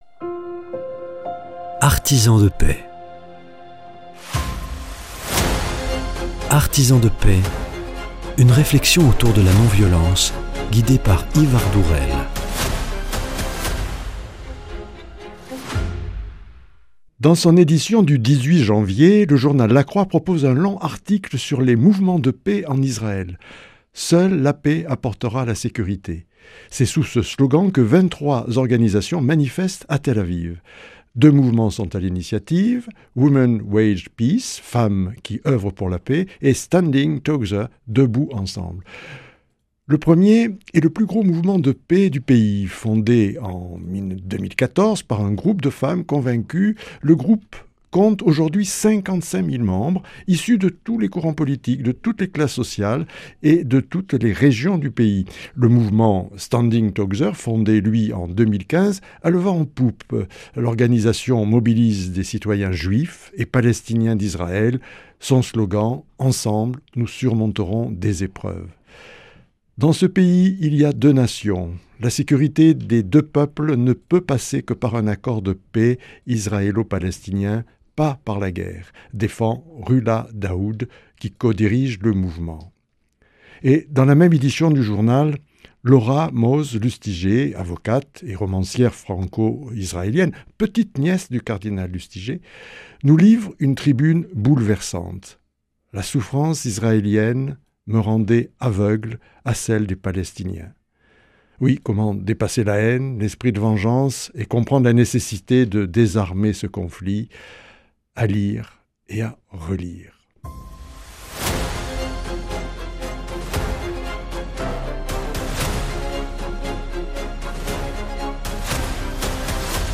Au cœur du conflit, même en Israël, on peut agir pour la paix. Comment le CCFD-Terre solidaire, agit dans les domaines de la justice économique ; dernier entretien de la série.